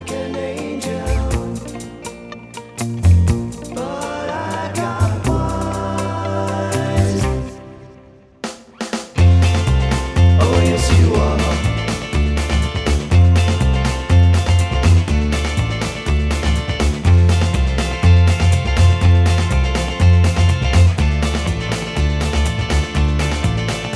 Minus Lead Guitar Pop (1960s) 2:29 Buy £1.50